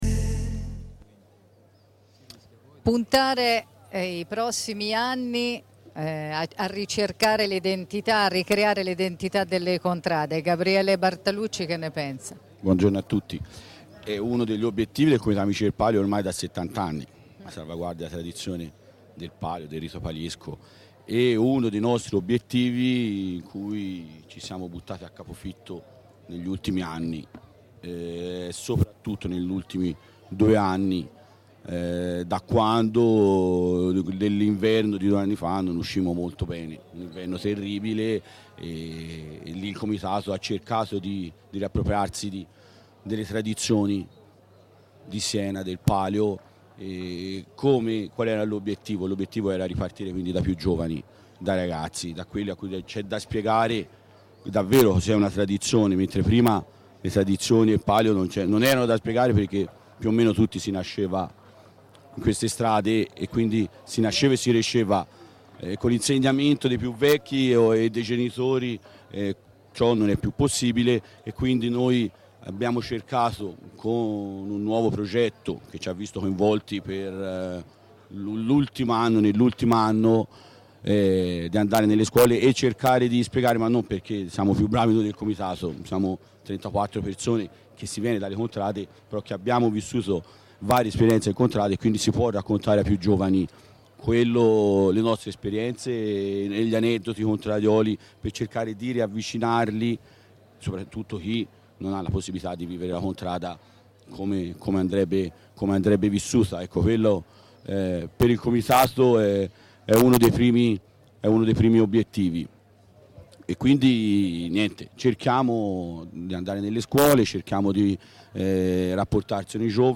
Diretta dai palchi